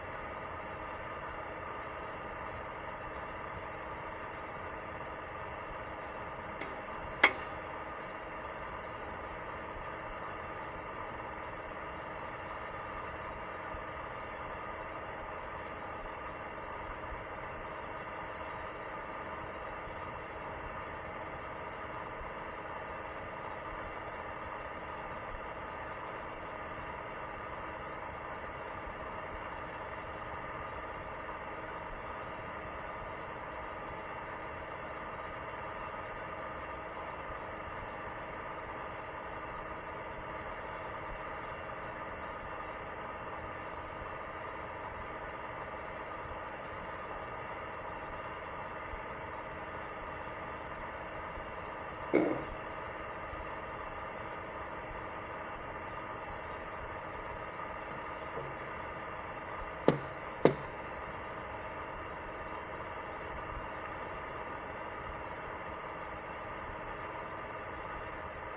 夜寝ている時や朝目が覚めてうとうとしている時等に鼻の奥の方でブ～ンと音がして、目が覚めてしまうと、難眠（不眠）の原因を説明。
小生、カセットレコーダーにて録音した、ブ～ン音を再生しながら（右参考）。